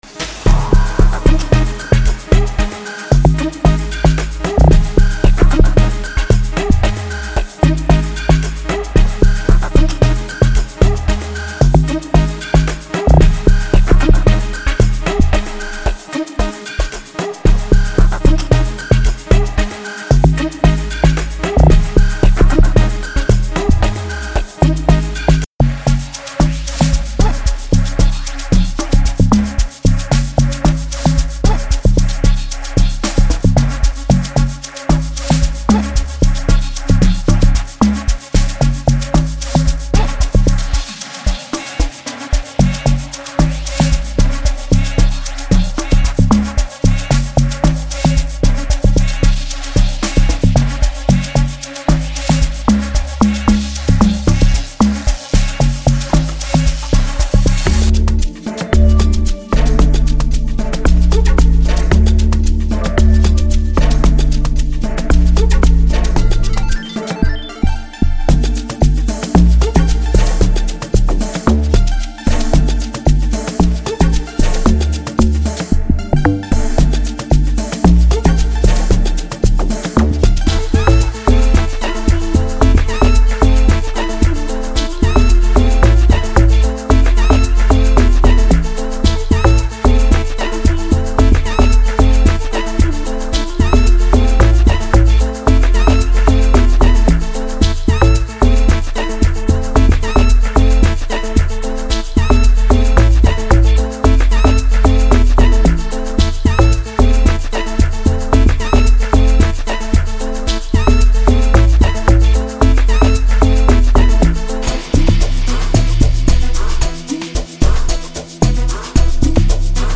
• 99 WAV Loops (Including Drums, Melodics, Vocals, and FXs)